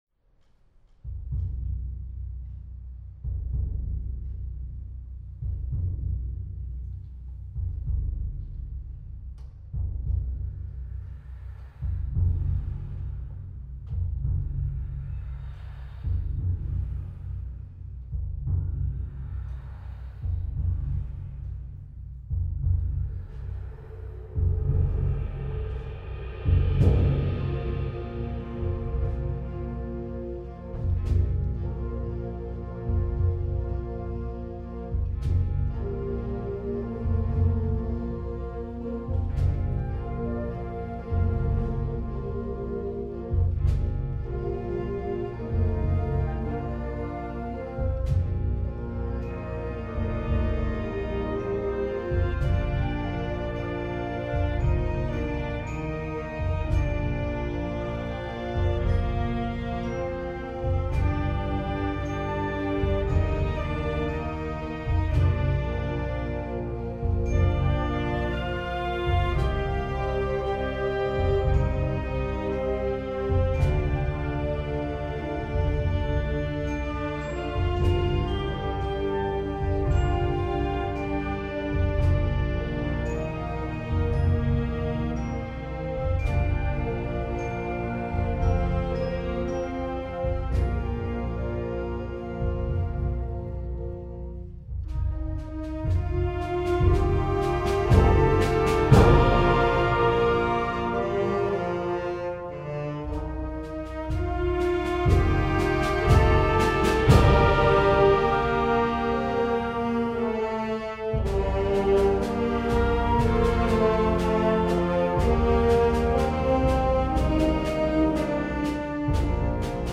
KONZERTMUSIKBEWERTUNGEN - JUGENDBLASORCHESTER
>live